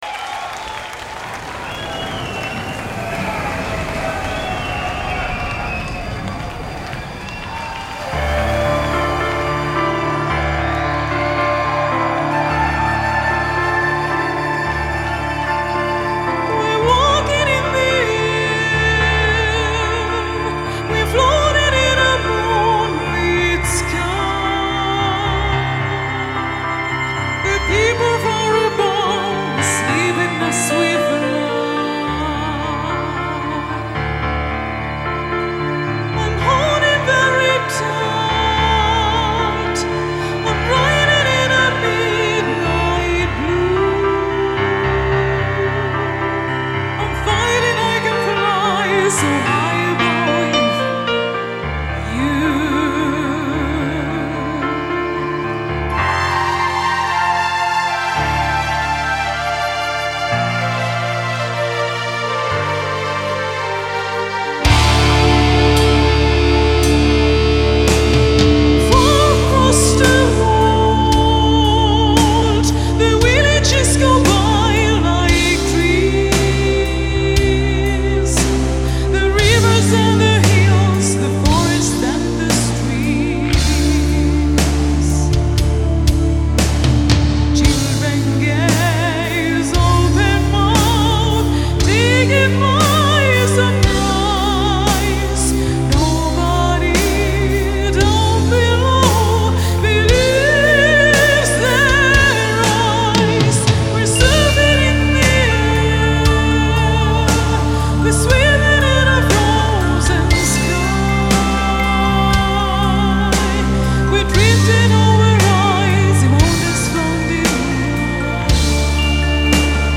Rock version